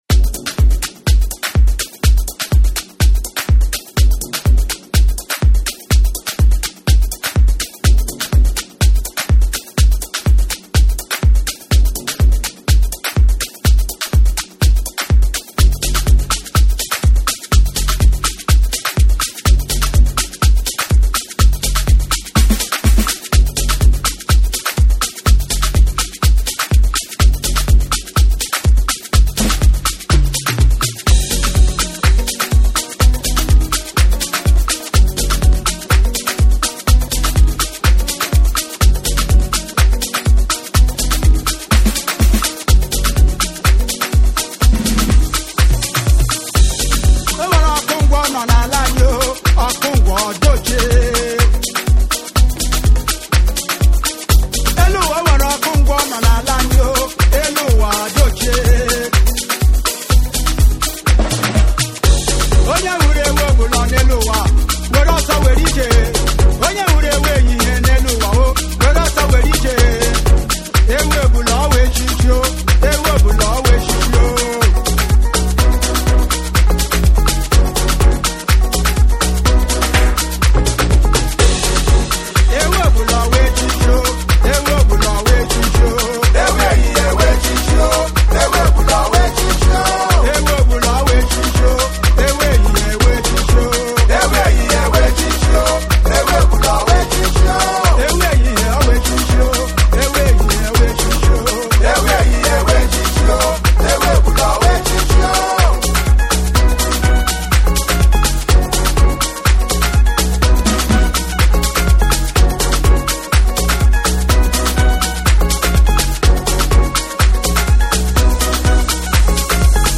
一足早く全曲フルで聴きましたが、終始流れるハッピーなヴァイブス。原曲の魅力を最大限に引き出すコンテンポラリーな電子化。
WORLD / BREAKBEATS / NEW RELEASE(新譜)